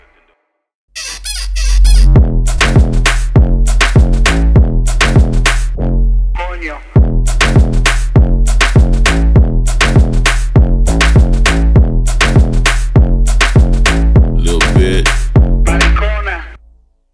• Качество: 320, Stereo
мощные басы
Bass House
качающие
клубняк
G-House